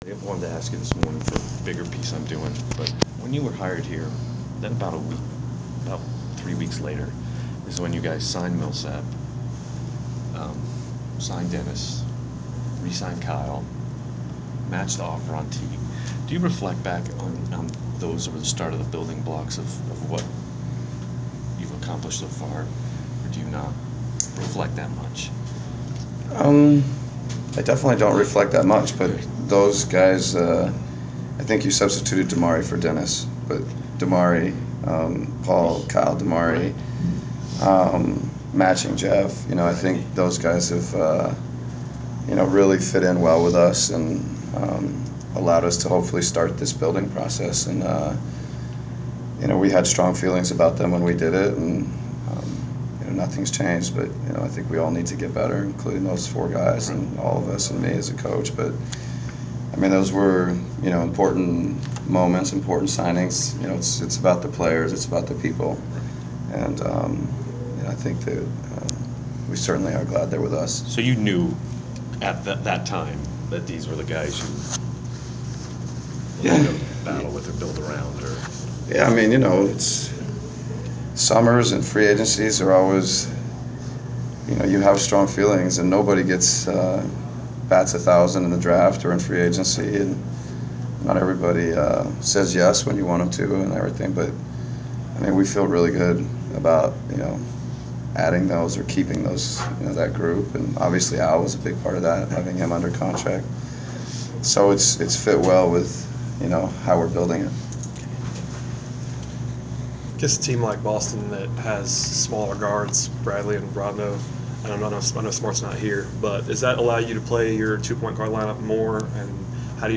Inside the Inquirer: Pre-game press conference with Atlanta Hawks’ coach Mike Budenholzer (12/2/14)
We attended the pre-game press conference of Atlanta Hawks’ coach Mike Budenholzer before the team’s home contest against the Boston Celtics on Dec. 2.